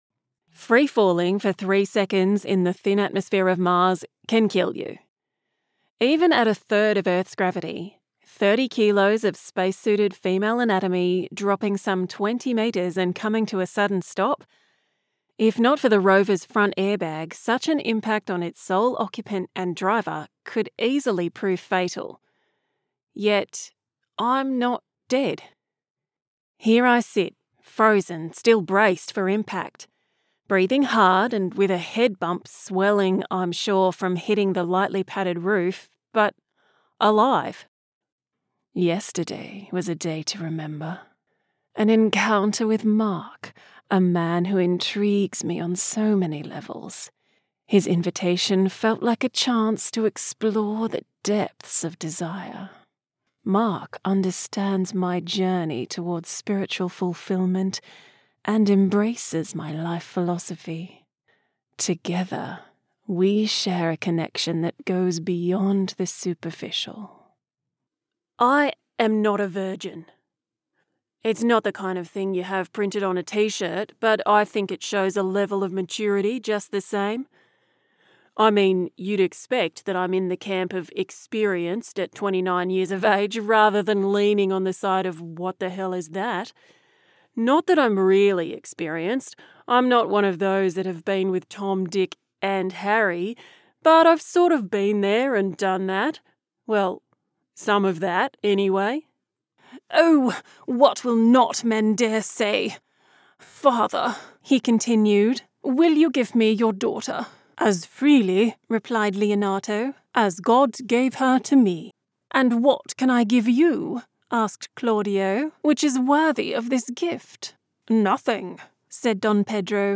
⬇ Download Character Demo (MP3) 📘 Audiobooks – Fiction : Rich storytelling with warmth and emotional depth.